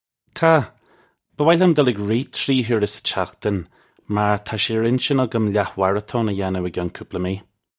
Tah. Buh woy lyum dull ig reeh chree hoora suh chakhtin mar tah shay urr in-chin uggum lah-warra-tone uh ya-noo ih gyunn koopla mee. (U)
This is an approximate phonetic pronunciation of the phrase.